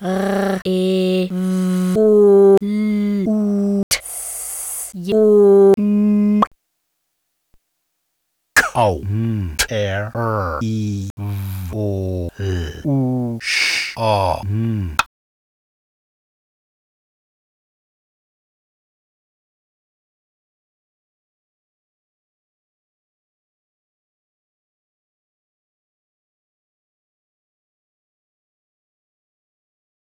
ʁevolʊtsjon. kaʊ̯nteəʳɹivolʊʃʌn.